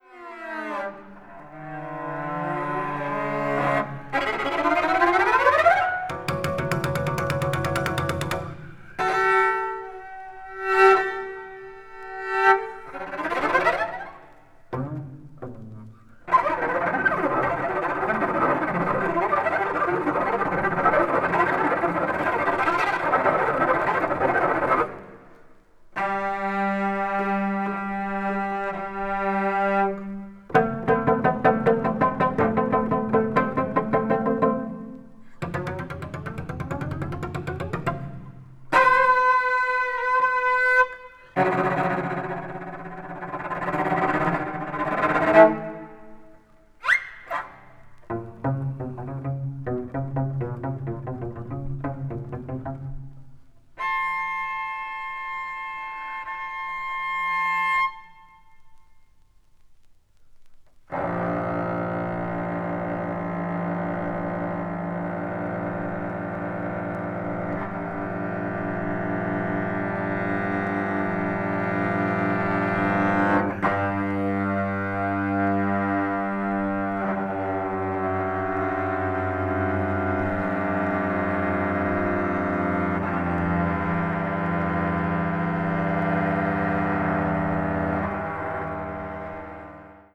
can even be appreciated with a free jazz sensibility
The recording quality is also outstanding.